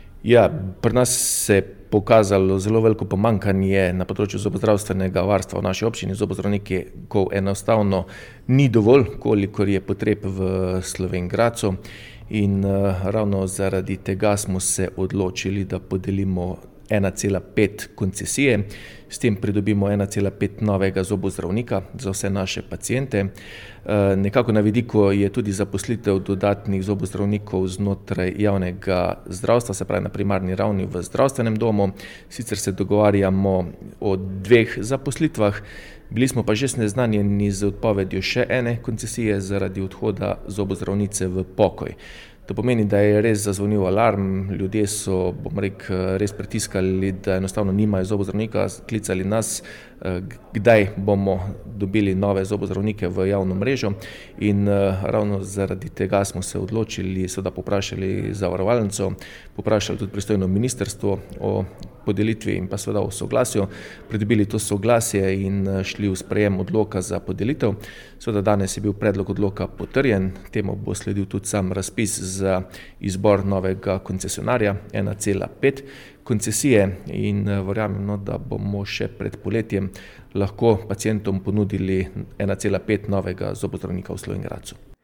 Več pa župan Tilen Klugler.